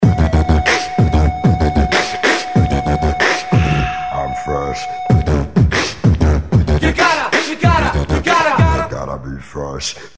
• Качество: 128, Stereo
веселые
битбокс